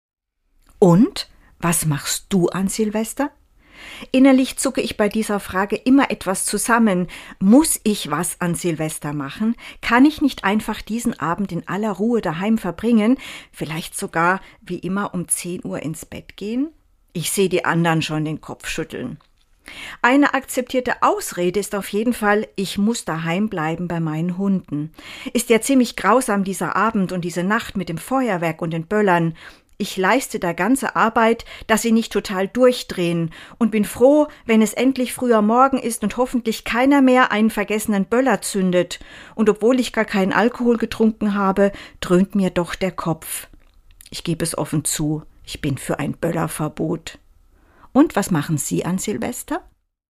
Dezember 2025 Autorin und Sprecherin ist